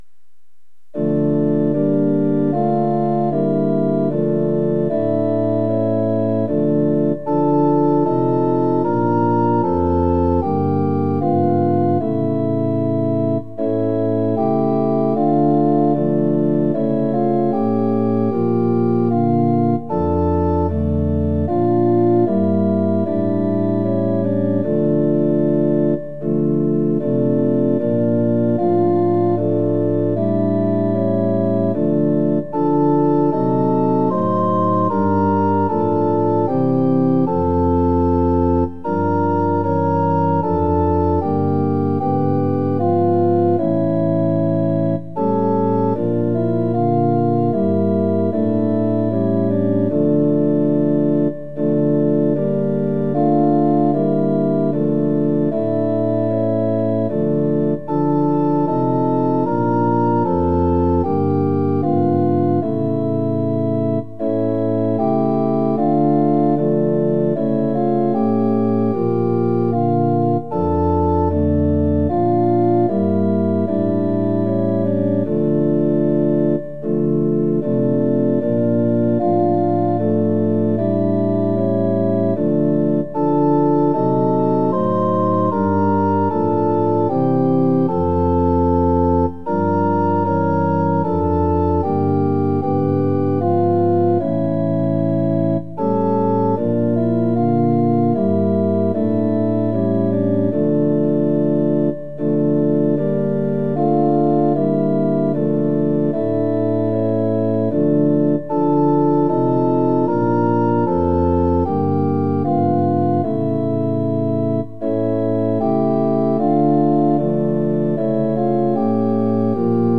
◆　４分の４拍子：　１拍目から始まります。